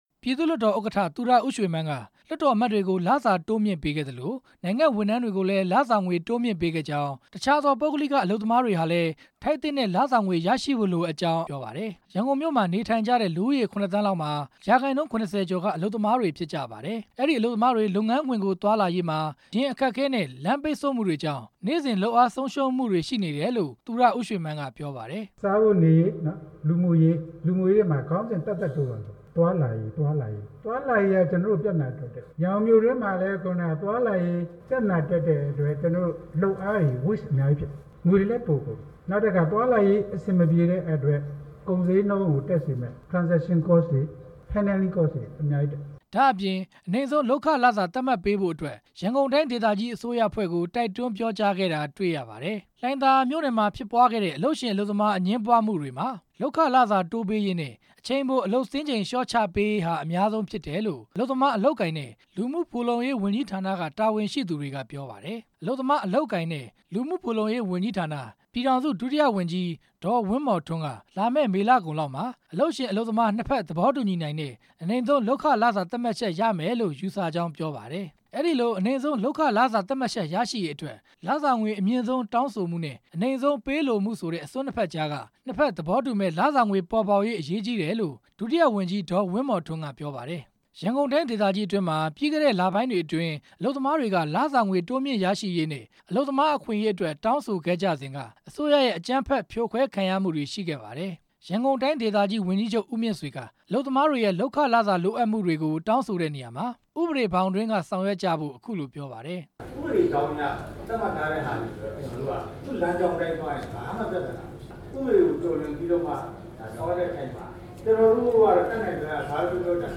ရန်ကုန်မြို့ လှိုင်သာယာမြို့နယ် ကနောင်ခန်းမမှာ ရန်ကုန်တိုင်းဒေသကြီးအစိုးရအဖွဲ့၊ အလုပ်ရှင်၊ အလုပ်သမားကိုယ်စားလှယ်တွေနဲ့ တွေ့ဆုံ တဲ့ဆွေးနွေးပွဲမှာ ပြည်သူ့လွှတ်တော်ဥက္ကဋ္ဌ သူရဦးရွှေမန်းက ပြောခဲ့တာပါ။